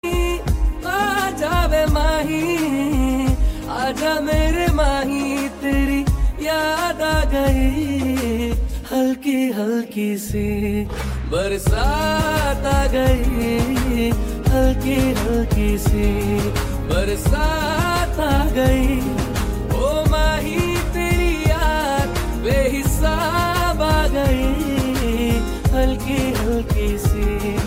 tranquil melody
Categories Hindi ringtones